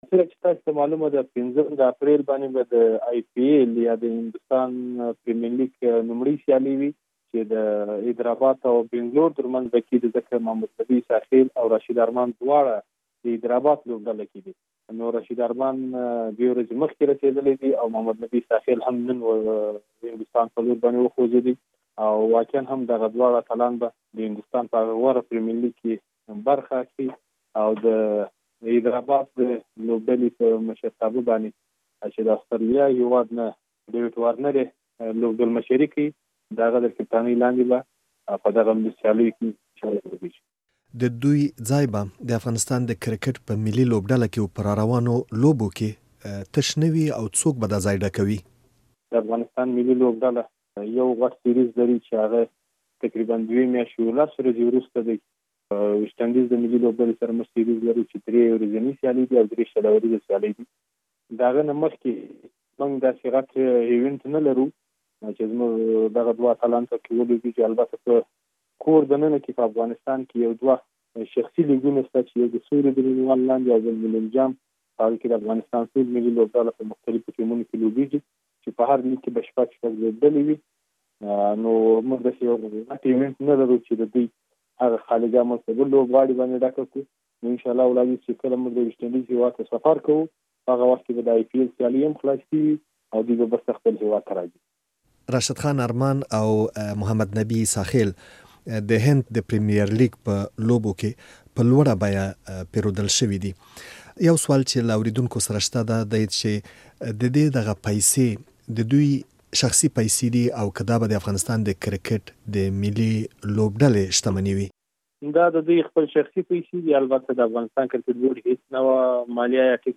مرکه.